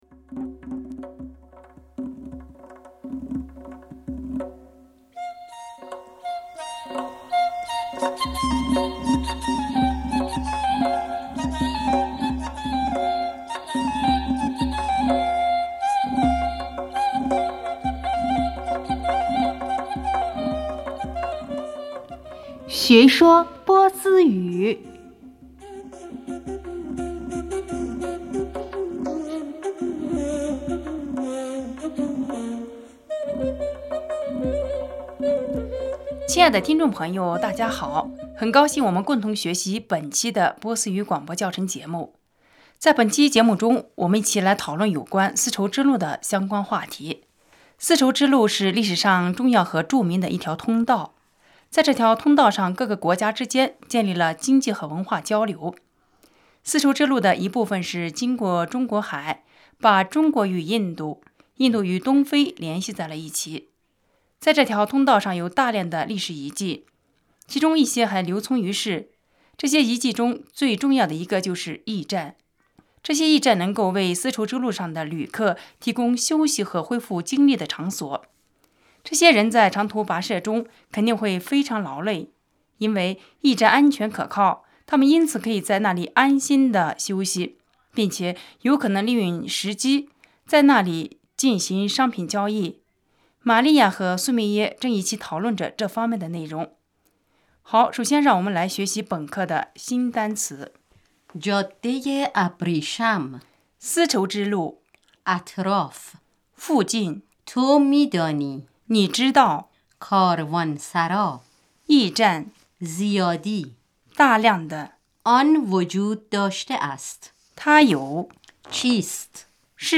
很高兴我们共同学习本期的波斯语广播教程节目。在本期节目中，我们一起来讨论有关丝绸之路的相关话题。